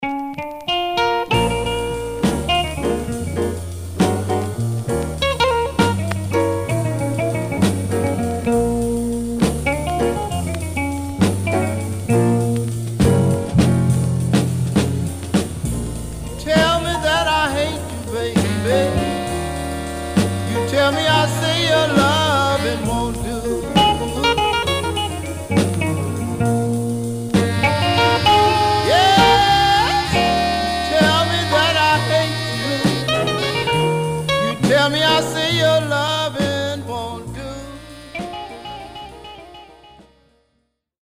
Some surface noise/wear
Mono
Soul